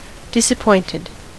Ääntäminen
US : IPA : [ˌdɪ.sə.ˈpɔɪnt.ɪd]